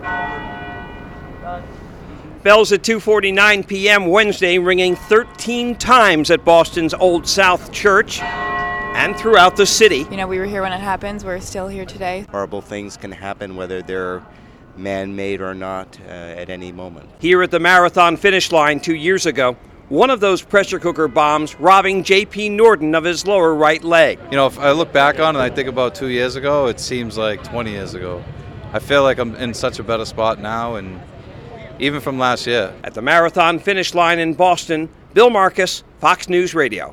Finish Line, Boylston Street, Boston, April 15, 2015.